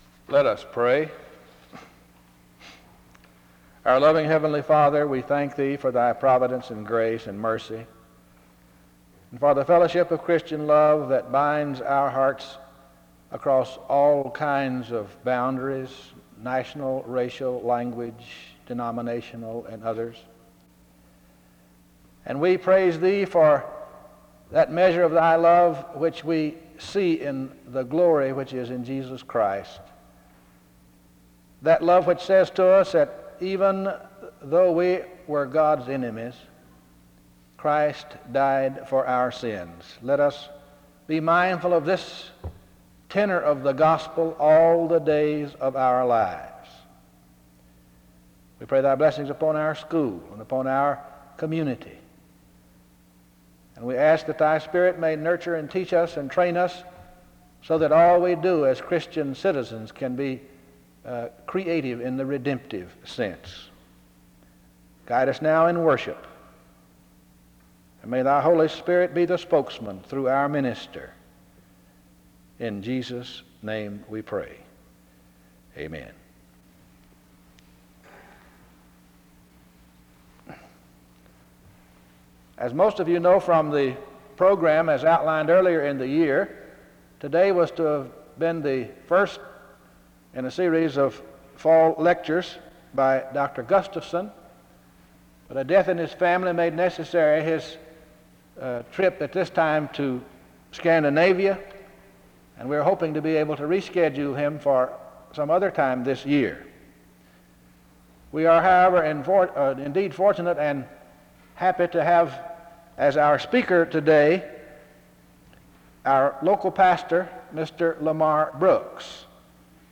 The service begins with a prayer (0:00-2:20).
He concludes by explaining the need for obedience and thankfulness (19:34-21:22). He closes in prayer (21:23-22:34).